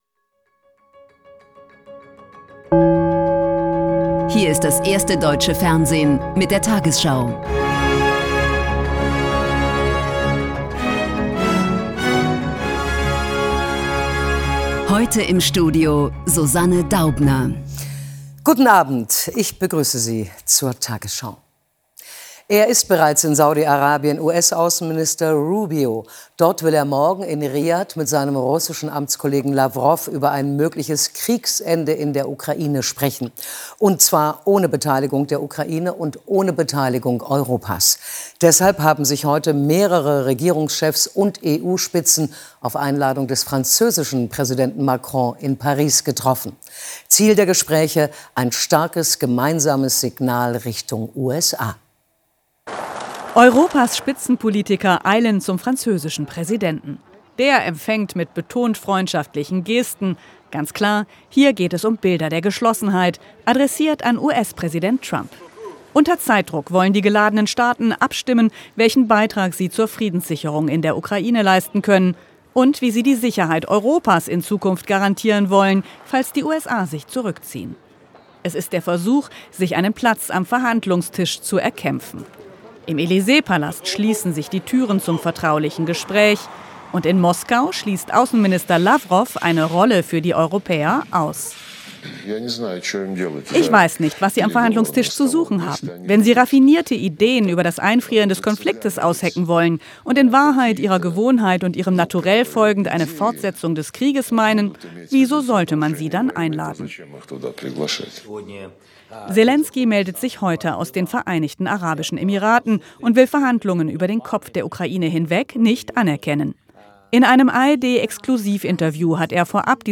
Die 20 Uhr Nachrichten von heute zum Nachhören. Hier findet ihr immer, was am Tag aktuell und wichtig ist in den News.